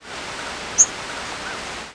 Short rising seeps
Yellow-rumped Warbler ex1 ex2